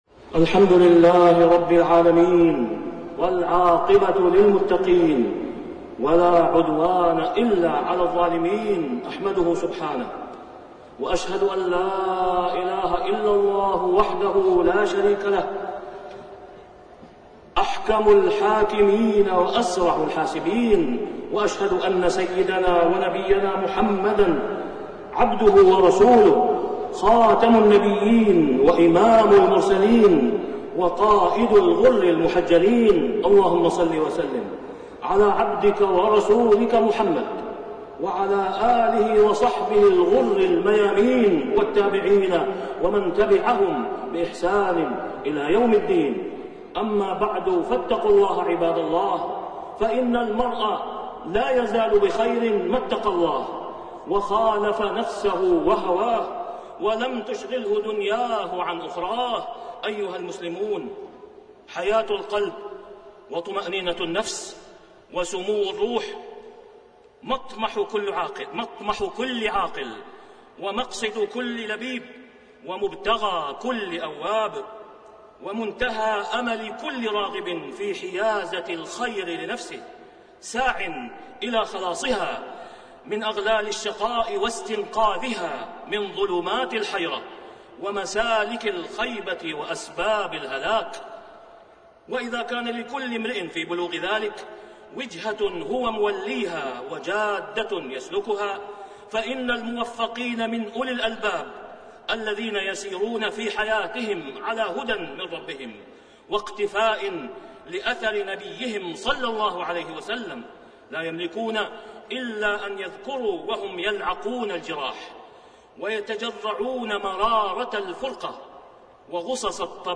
تاريخ النشر ١ رجب ١٤٣٢ هـ المكان: المسجد الحرام الشيخ: فضيلة الشيخ د. أسامة بن عبدالله خياط فضيلة الشيخ د. أسامة بن عبدالله خياط الإعتصام بالكتاب والسنة سبيل النجاة